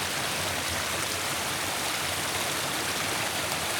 ambientrain.wav